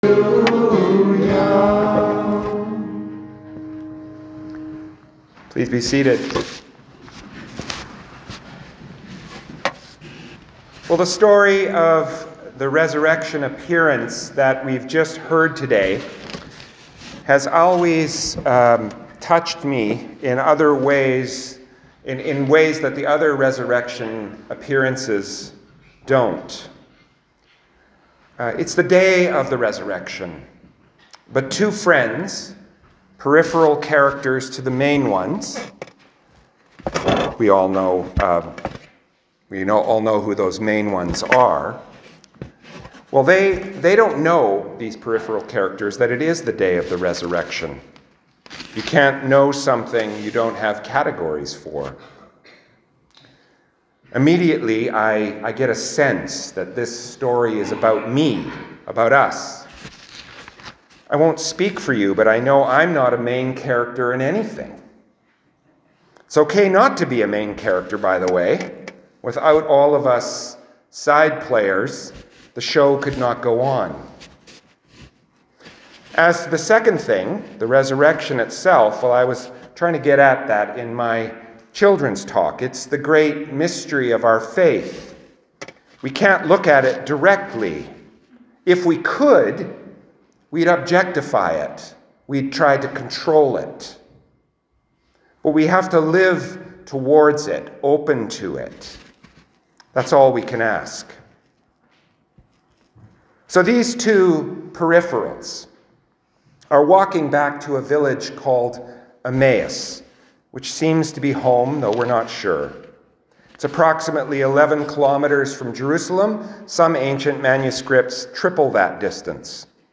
Sermons | St Philip Anglican Church